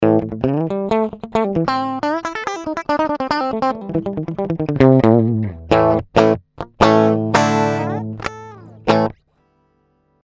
хорошим датчикам на хорошем инструменте - никакая помощь не требуется вот мой j custom , в диджилаб... с не самыми мягко говоря новыми струнами daddario ) Вложения ibanez di.mp3 ibanez di.mp3 400,7 KB · Просмотры